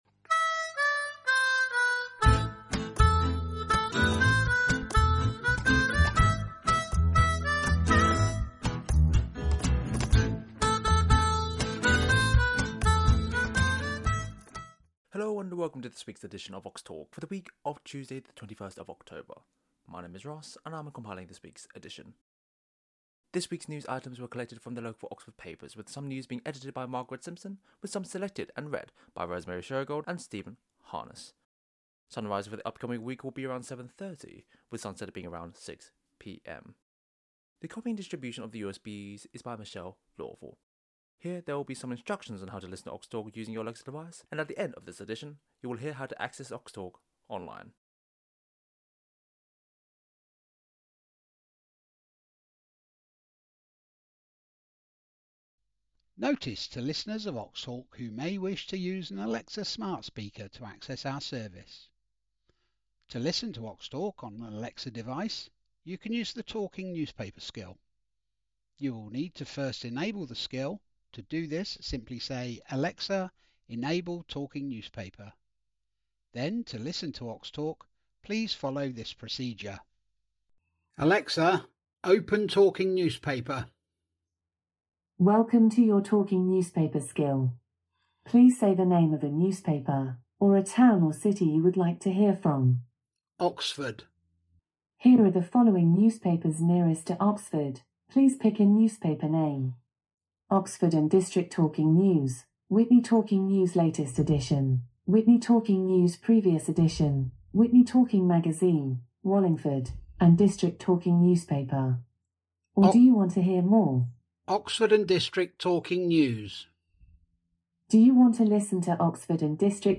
21st October 2025 edition - Oxtalk - Talking newspapers for blind and visually impaired people in Oxford & district